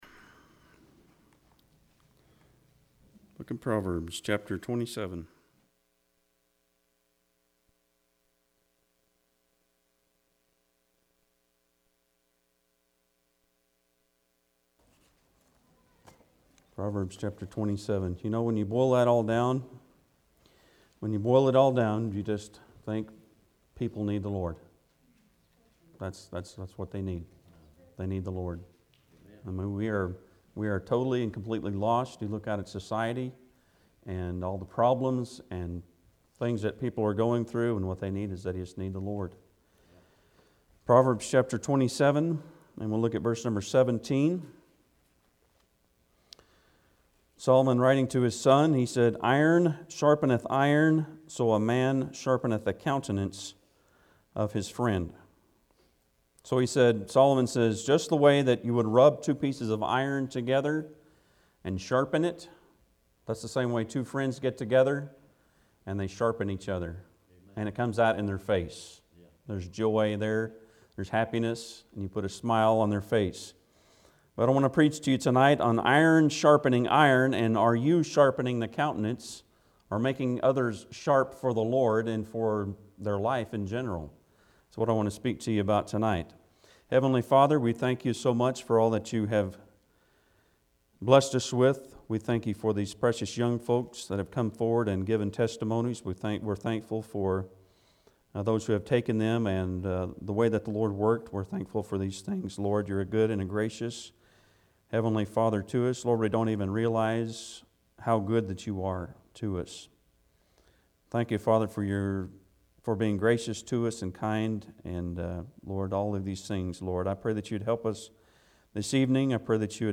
July 30, 2017 Iron Sharpens Iron Passage: Proverbs 27:17 Service Type: Sunday pm Bible Text: Proverbs 27:17 In Proverbs 27:17 Solomon tells us the importance of a good friend.